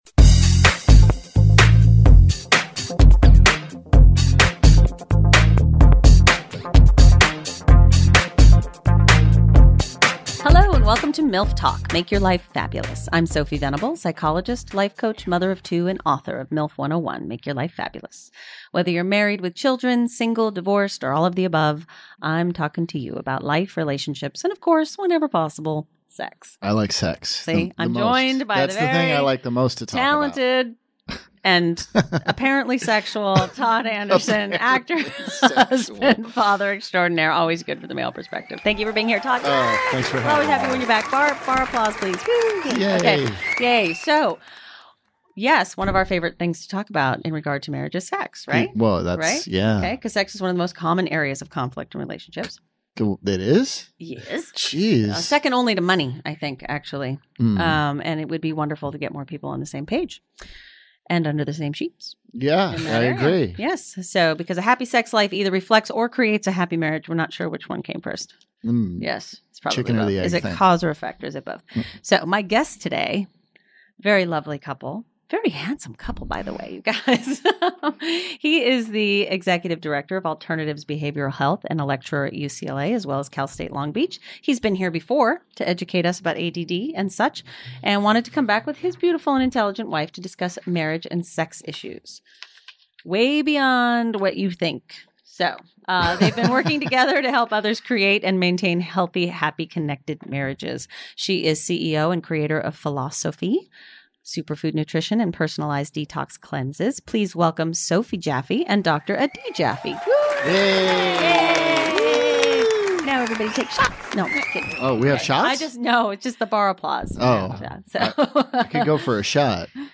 a very candid conversation about their struggles and triumph in their marriage due to sex addiction.